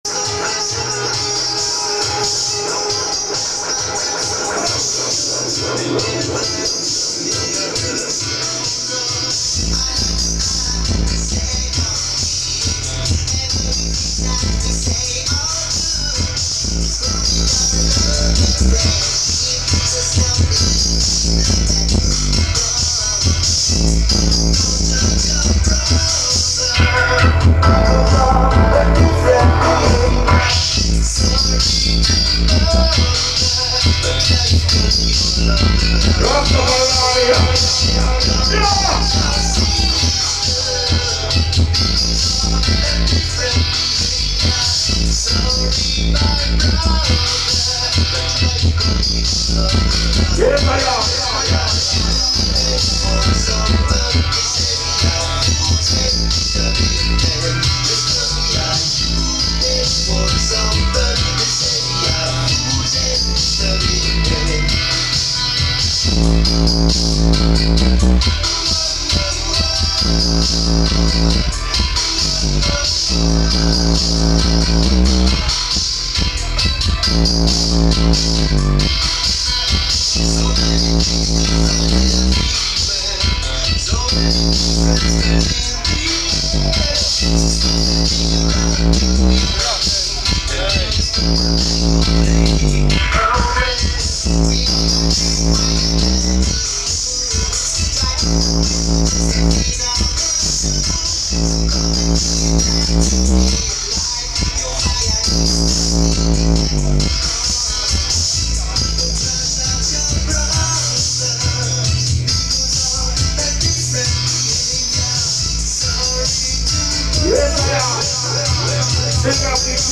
Jah Massive Soundsystem - Karlssons Kök - Midsummer dance